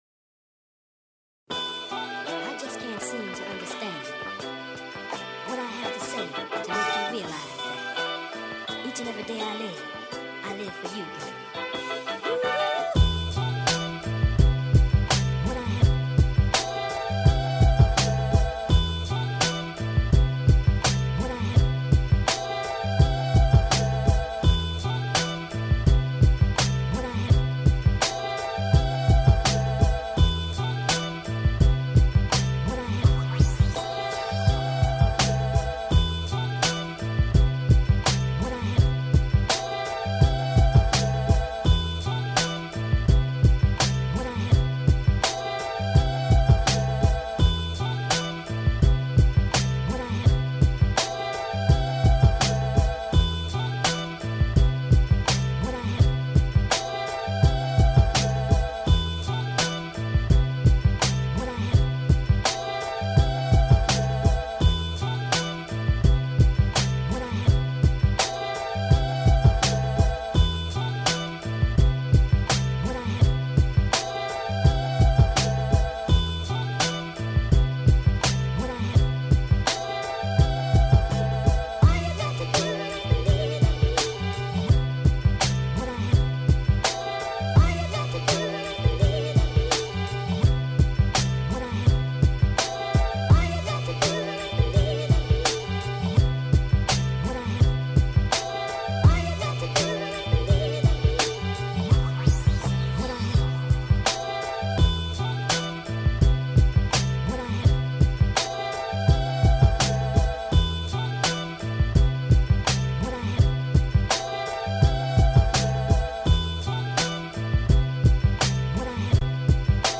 Chopped soulful samples with melodic arrangement